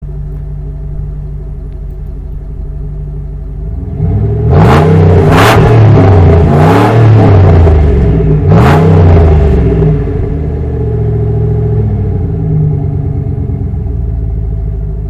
SMS-ton aufs Handy laden...